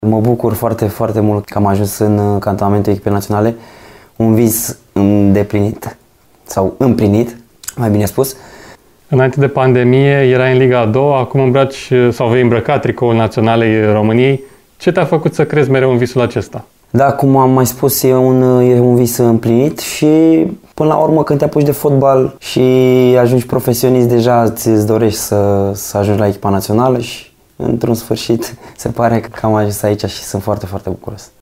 au oferit declarații la FRF TV
la sosirea în cantonamentul naționalei